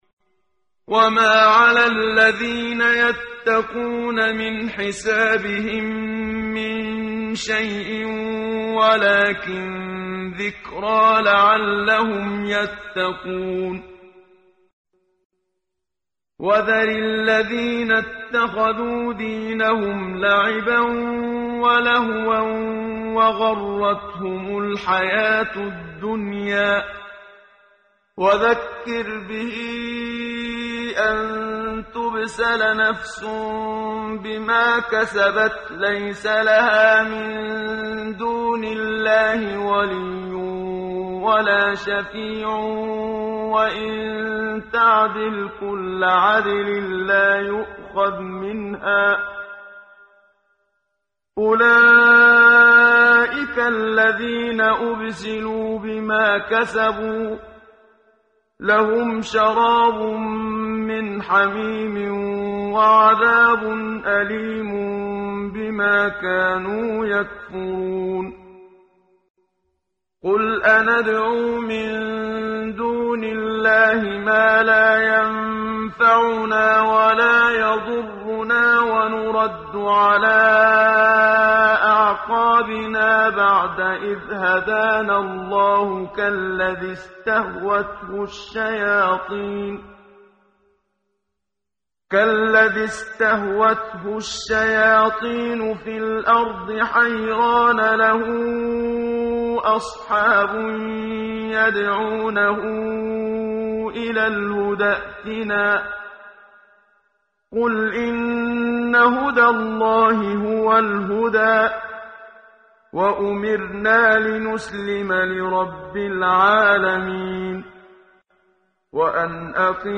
ترتیل صفحه 136 سوره مبارکه انعام (جزء هفتم) از سری مجموعه صفحه ای از نور با صدای استاد محمد صدیق منشاوی